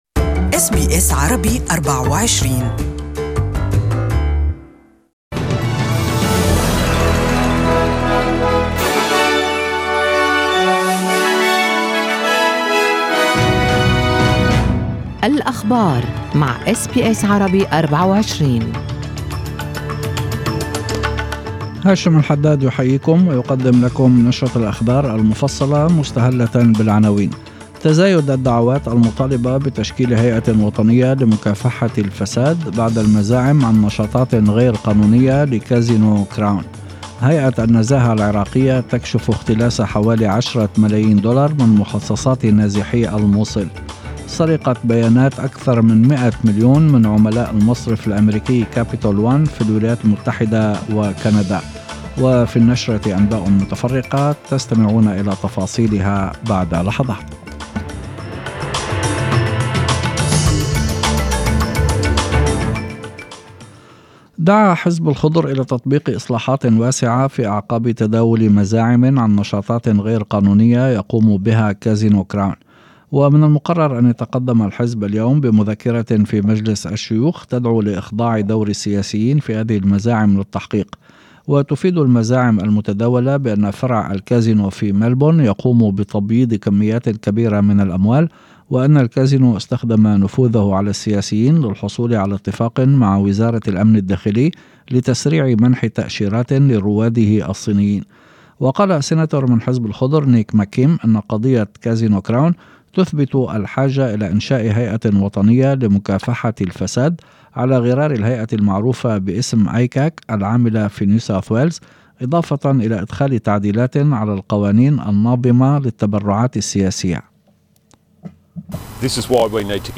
Evening News